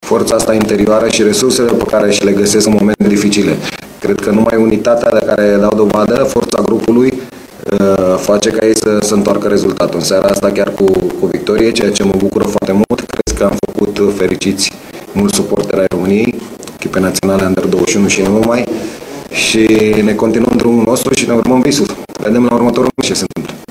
Selecționerul României U21, Adrian Mutu, a scos în evidență calitățile prestației de azi: